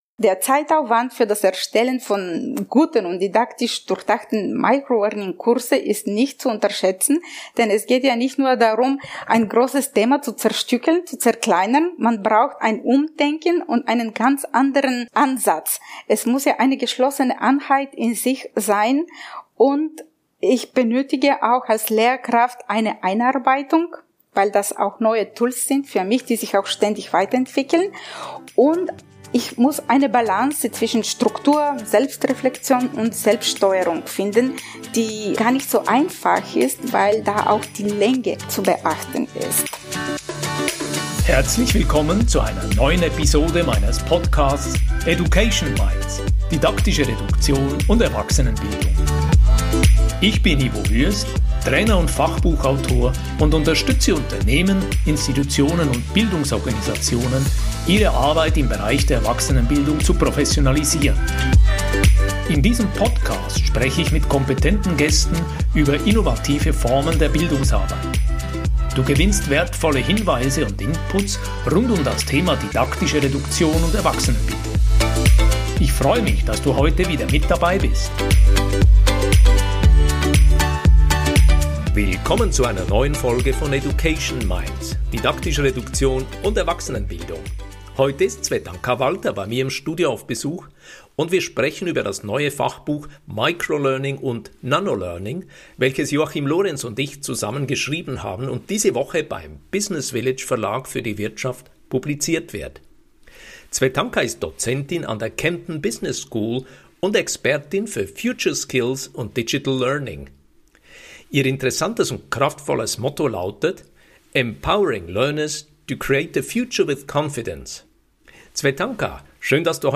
Höre am besten gleich rein in unser Gespräch über Didaktische Reduktion, Microlearning und wie es uns gelingt, Lernende zu empowern, ihre Zukunft aktiv und selbstbestimmend zu gestalten.